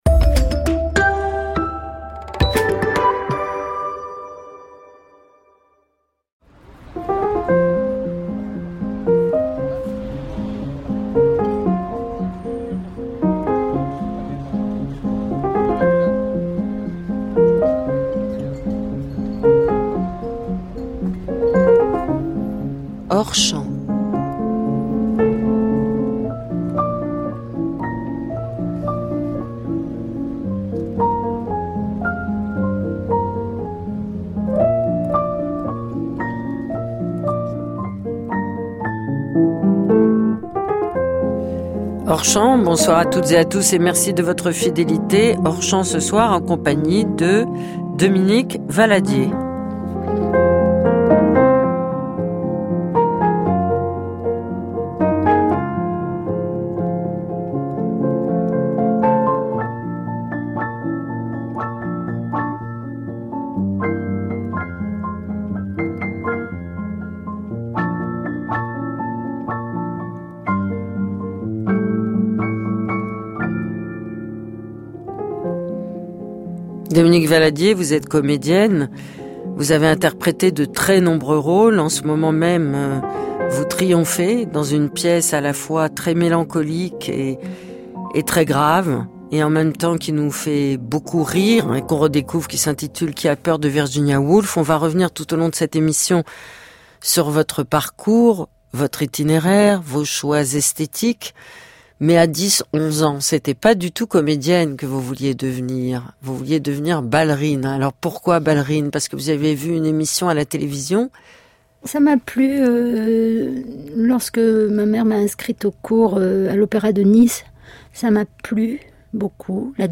Ancienne pensionnaire de la Comédie Française, la comédienne et actrice Dominique Valadié enseigne aujourd’hui au Conservatoire national supérieur d’art dramatique. Elle s’entretient avec Laure Adler.